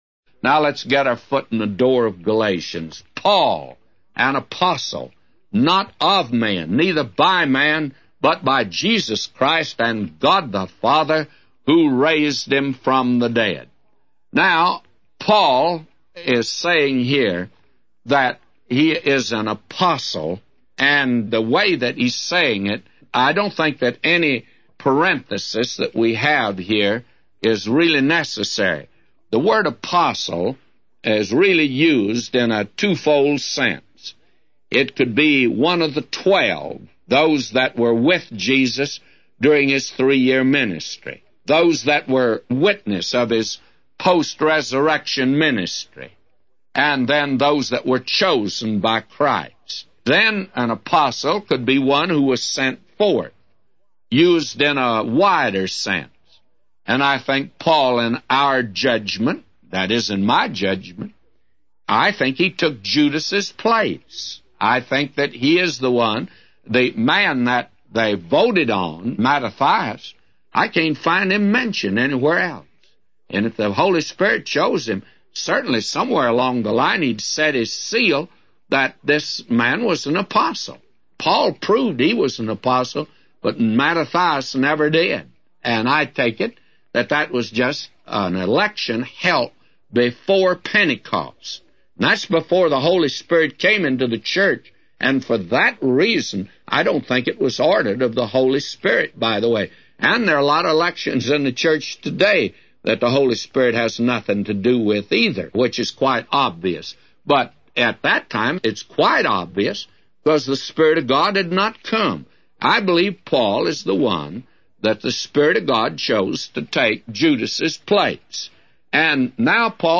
A Commentary By J Vernon MCgee For Galatians 1:1-999